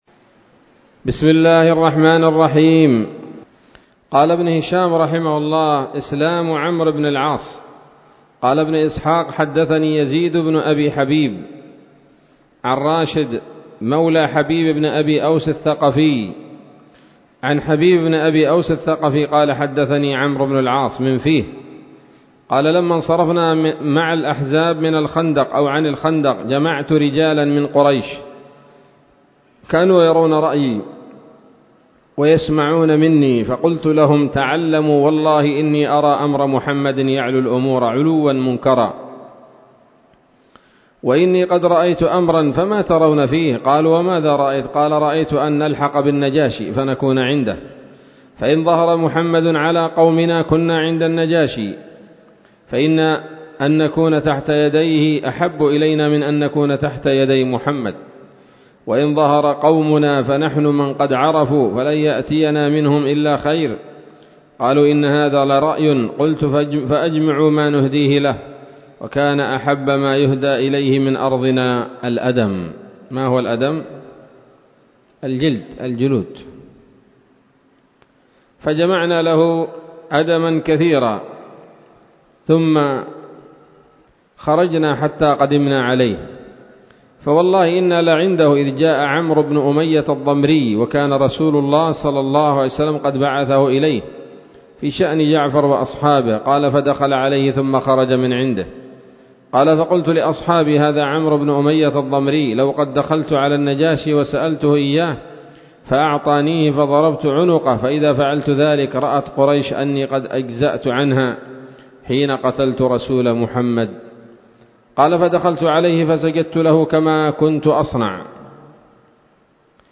الدرس الثامن عشر بعد المائتين من التعليق على كتاب السيرة النبوية لابن هشام